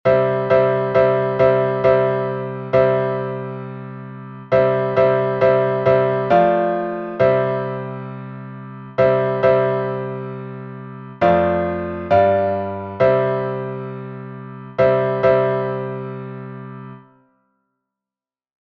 Обиходного напева